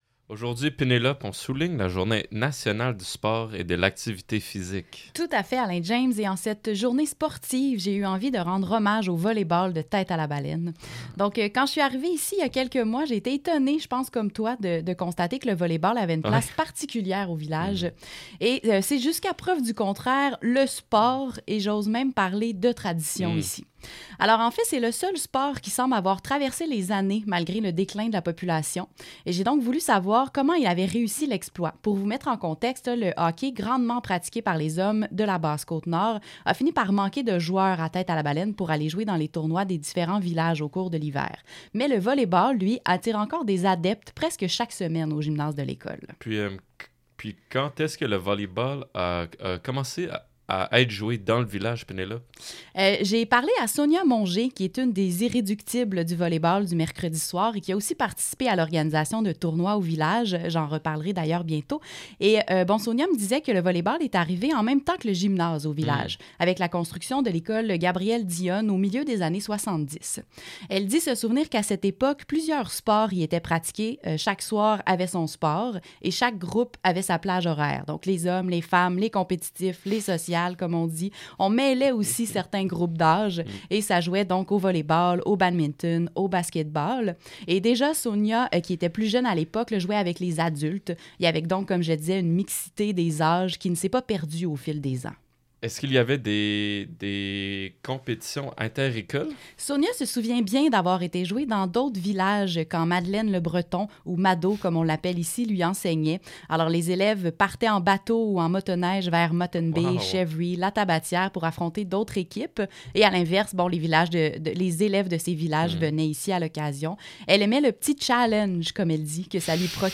Volleyball-segment-radio.mp3